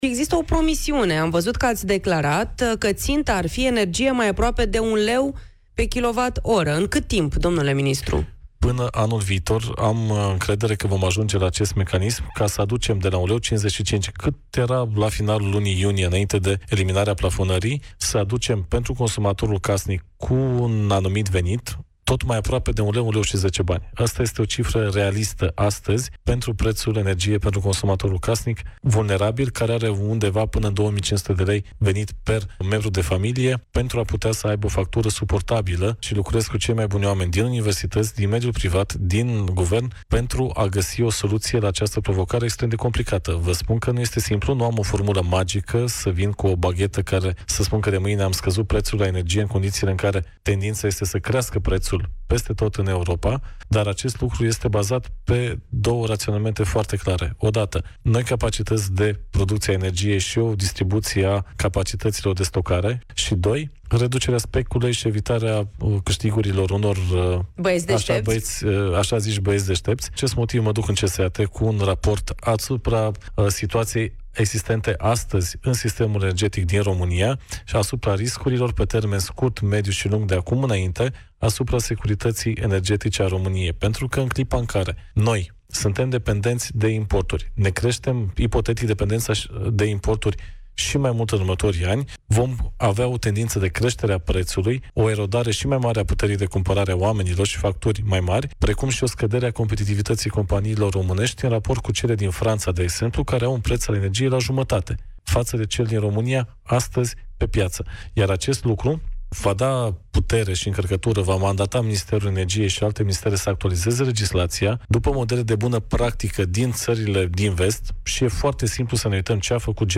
Prețul energiei electrice pentru consumatorii casnici cu venituri de până la 2.500 de lei ar urma să scadă la 1,10 lei pe kWh până în 2026, a spus ministrul Energiei, Bogdan Ivan, la emisiunea „Piața Victoriei” la Europa FM.